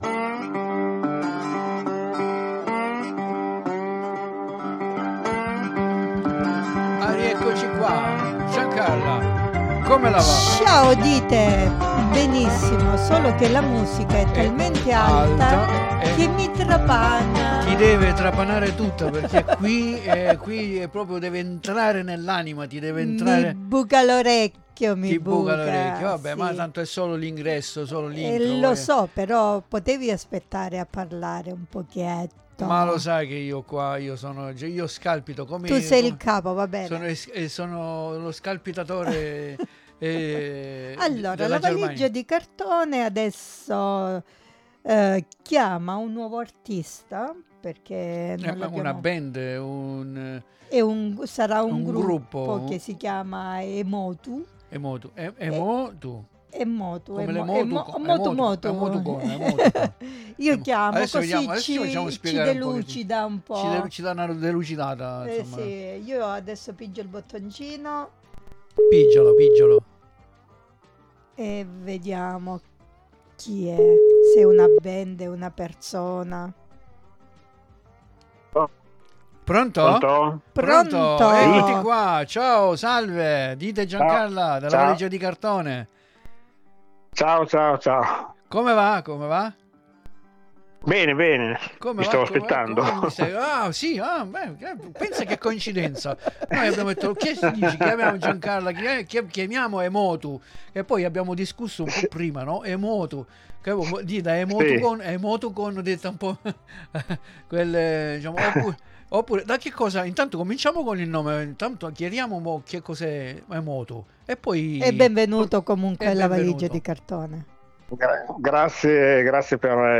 Audio Download (65,11 MB) INTERVISTA_Emotu.mp3 Sendetermine La valigia di Cartone 29.06.2025 11 Uhr